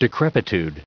added pronounciation and merriam webster audio
1154_decrepitude.ogg